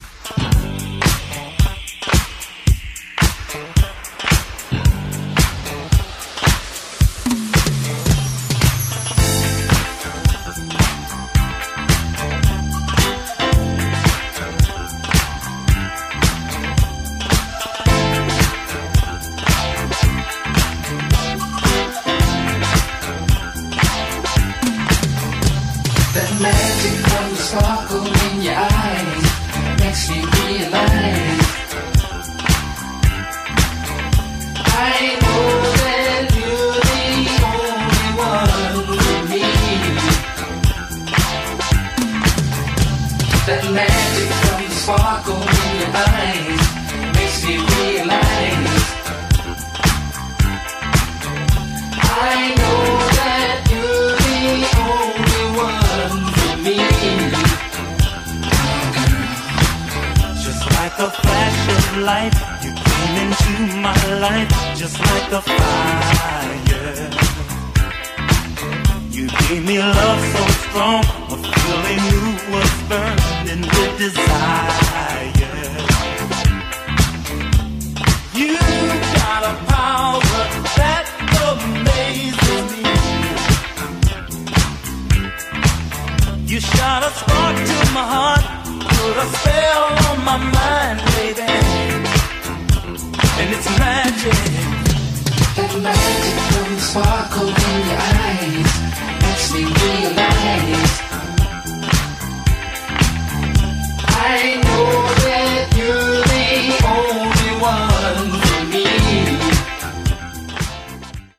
グルーヴィーで都会的なミッド・テンポのディスコ・チューン！